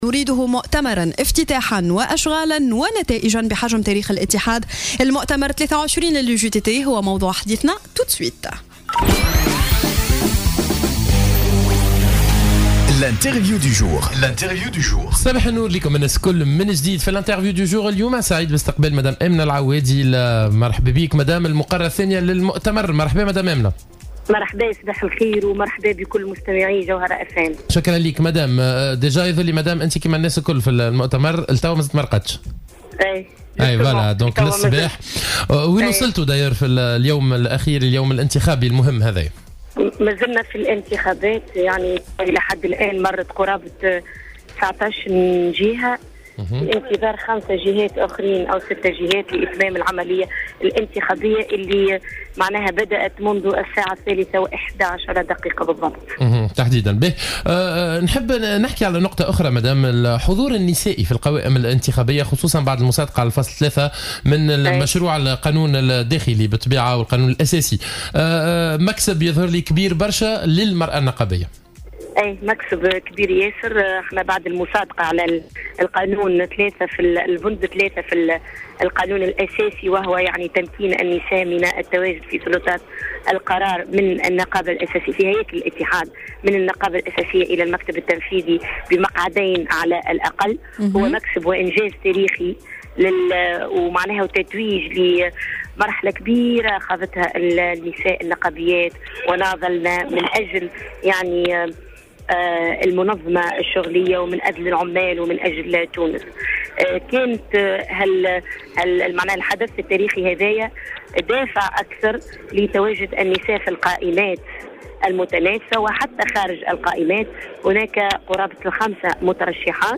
وأضافت في تدخل هاتفي بـ "صباح الورد" على "الجوهرة اف أم" أن التصويت بدأ فجر اليوم في انتظار استكمال تصويت باقي الجهات. وتوقعت انتهاء التصويت والفرز والتصريح بالنتائج في حدود منتصف النهار.